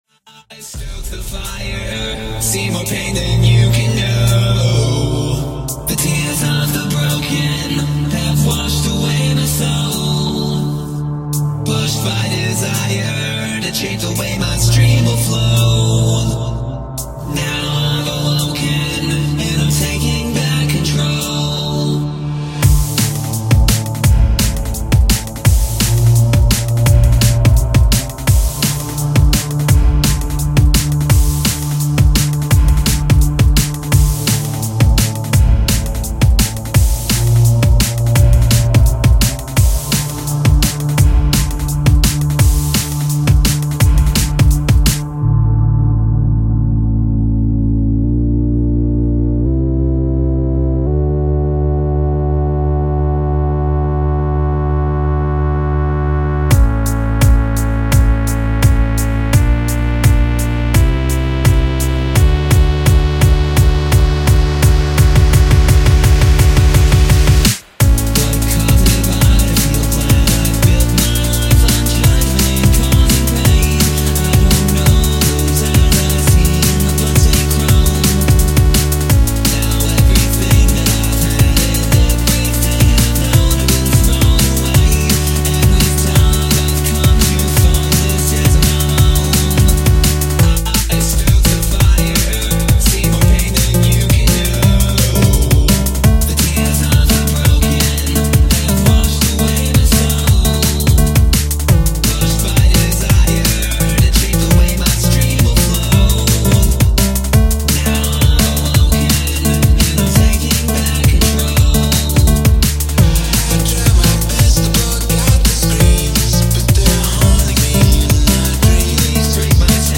genre:dnb
genre:remix